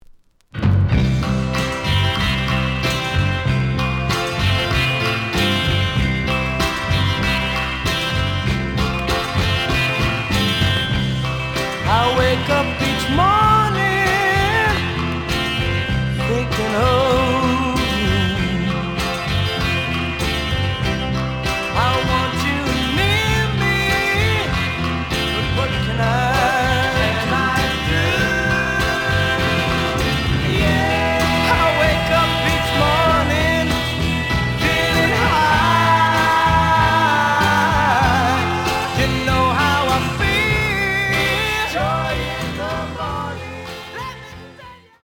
試聴は実際のレコードから録音しています。
●Genre: Rhythm And Blues / Rock 'n' Roll
両面に傷によるクリックノイズあり。